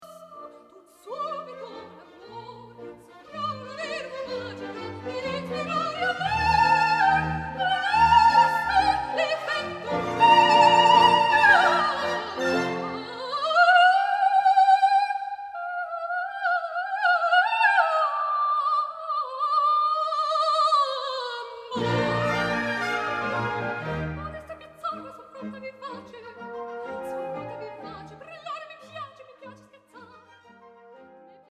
Sopran